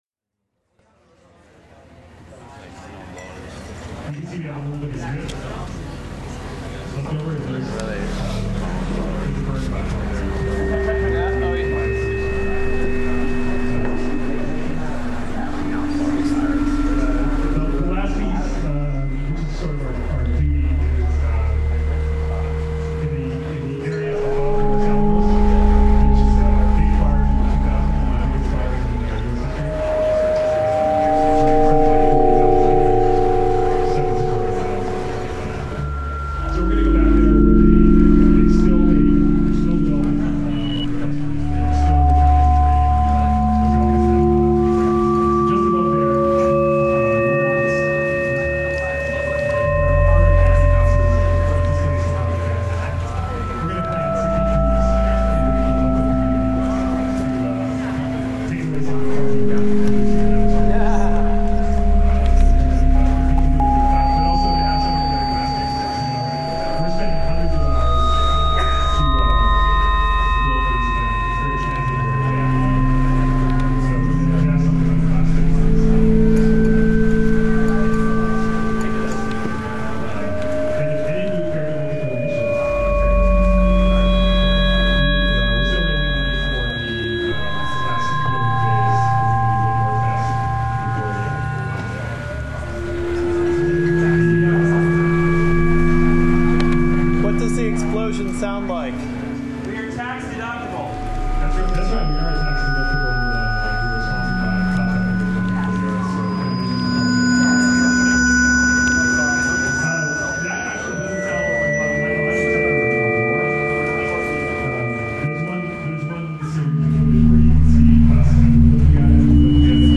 I then recorded the system harmonizing with ambient sounds.
I'm in the audience with laptop in my lap. It's running, listening to the speaker, the audience, me talking with my neighbor.
In betwixt, I had the laptop speakers on, so it added to the din.
This excerpt is from when the SimNuke video wouldn't quite show on the projector, so it was a discombobulated impromptu question/answer session.